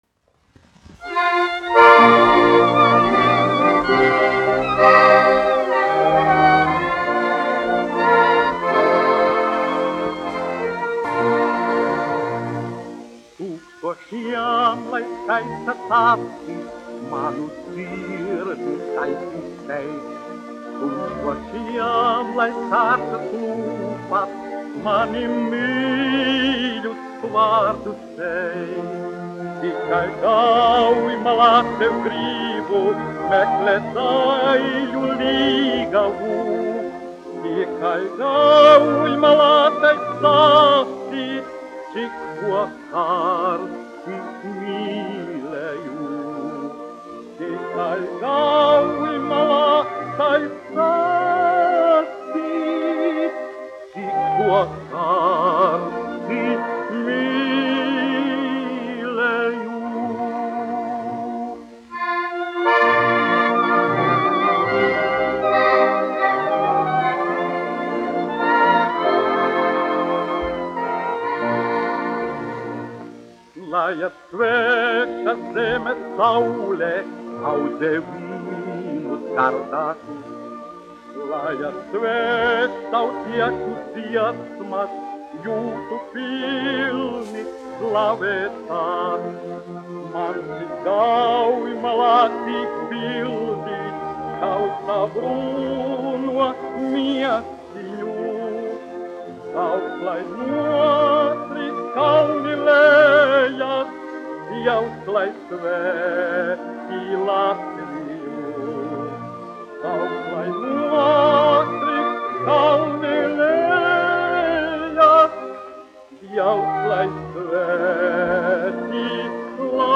1 skpl. : analogs, 78 apgr/min, mono ; 25 cm
Populārā mūzika -- Latvija
Latvijas vēsturiskie šellaka skaņuplašu ieraksti (Kolekcija)